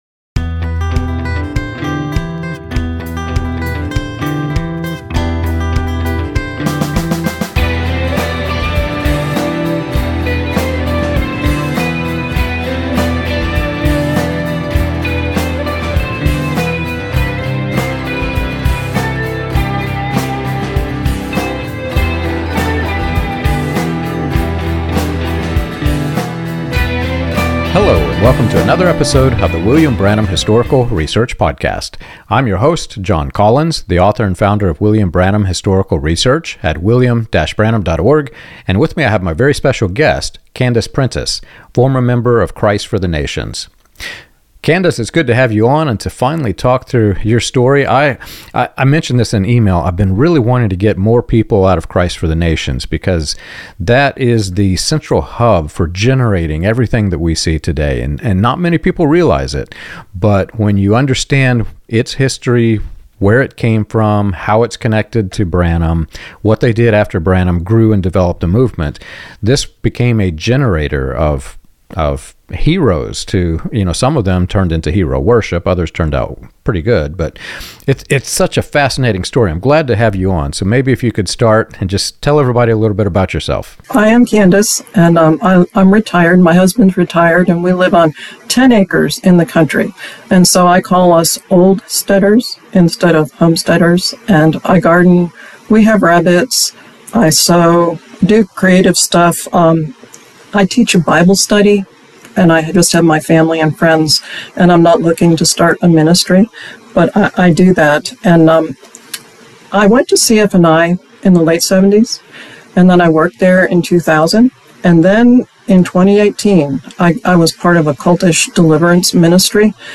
This conversation examines how authoritarian leadership, fear-based theology, and unrealistic expectations of constant blessing can damage faith and identity.